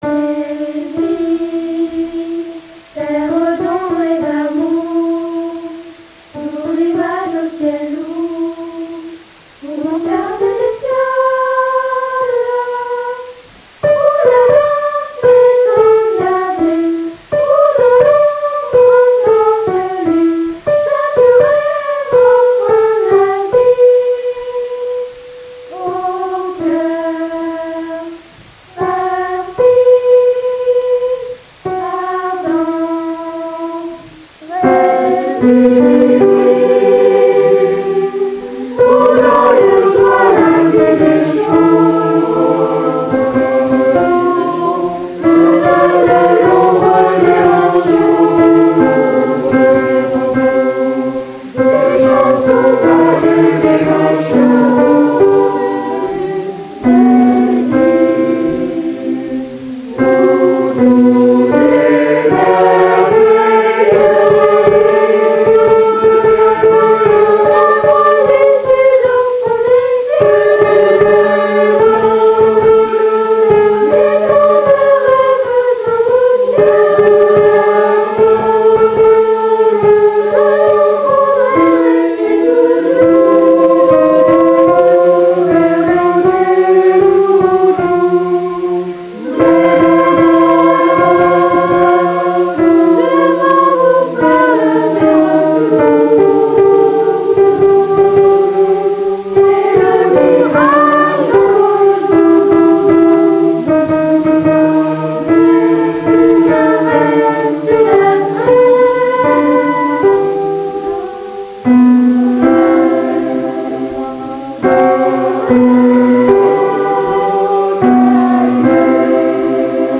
Voici nos enregistrements du 1er juin. Il n’y a rien d’exceptionnel, mais pour répéter cela peut être utile.
1er essai tous ensemble - Il y a encore du boulot :-)